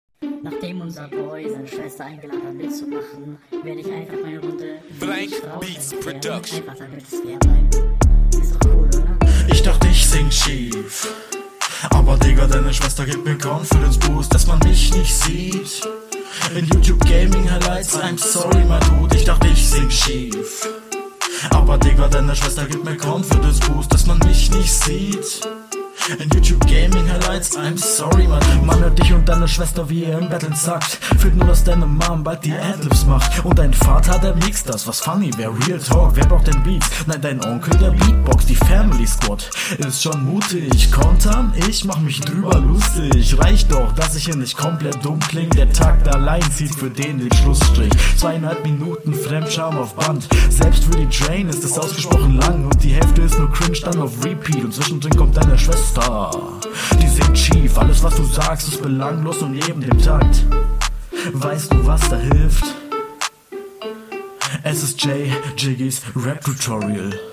Viel viel besser, schön im Takt.
Du solltest aufjedenfall an deiner Mische arbeiten.